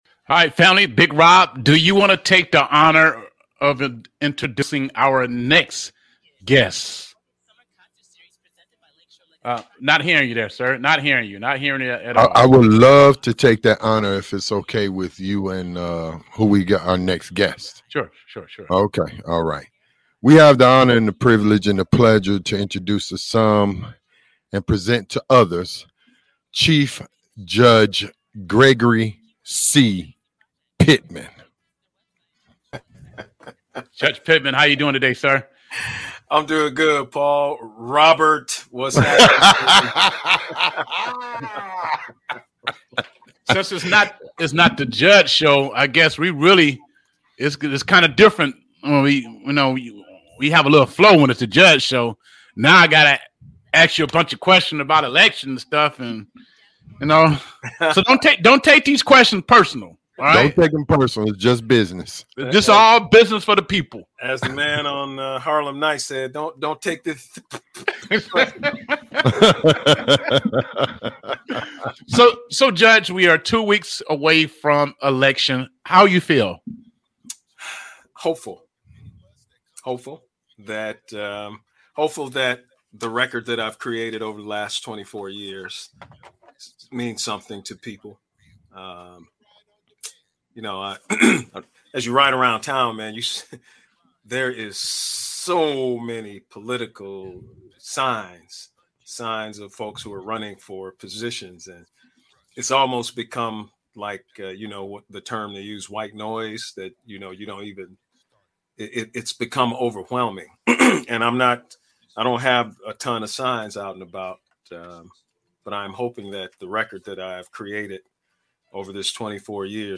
Interview with the Honorable Judge Gregory C. Pittman Candidate for Judge of 14th Circuit Court